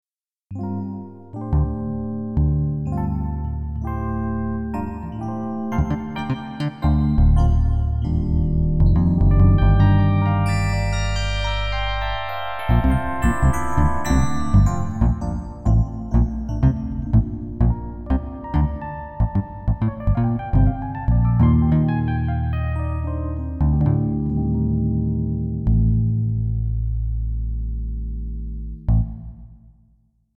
I made some example music with this setup, starting with a multitrack recording with rosegarden of some hexter sounds with ingenuity processing, and then combined like above with a combined hexter/PD sound with ingenuity coupled LADSPA effects, which is then input to rosegarden, and recorded on a new track.
example 1  strong combined hexter sounds